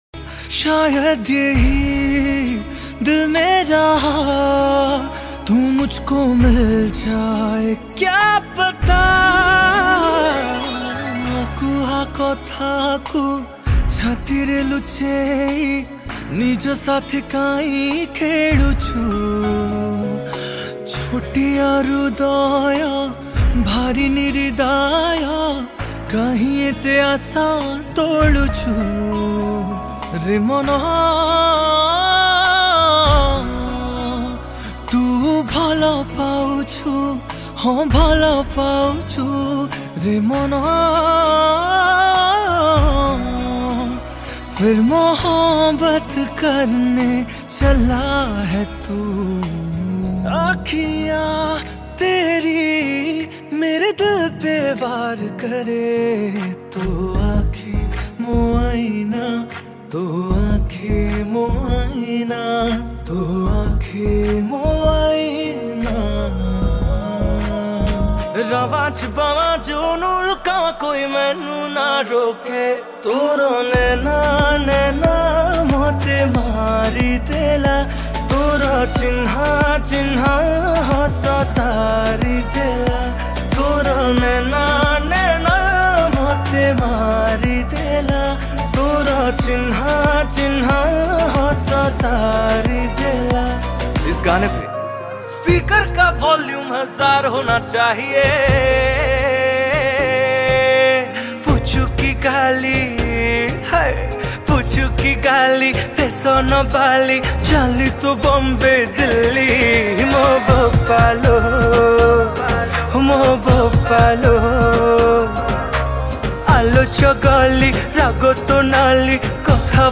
Cover Mp3 Song